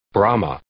Click any 'English' word, and you will hear how it is pronounced.
brahma.mp3